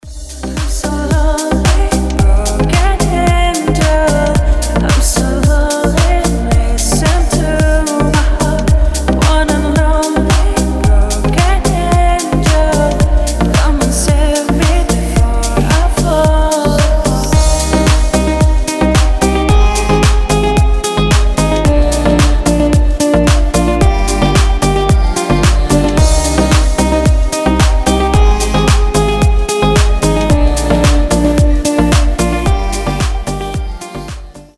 Cover , Поп